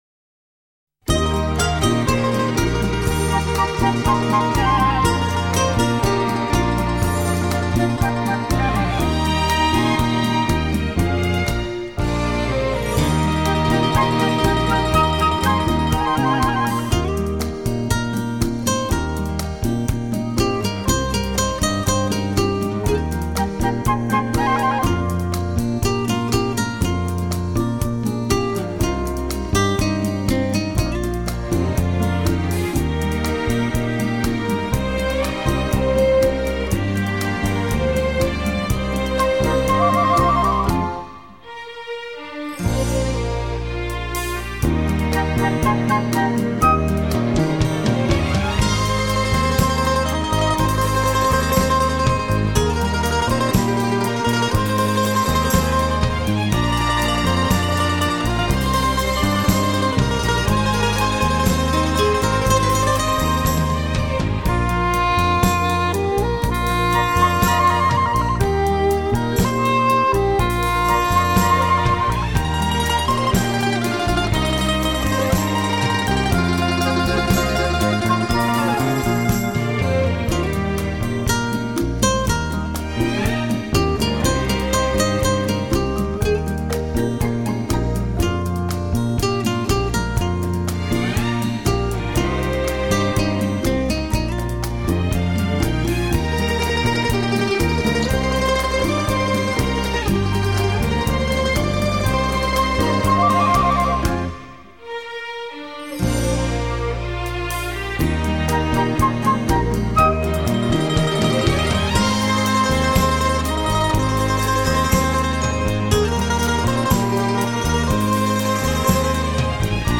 别出心裁的编曲 配上交响乐团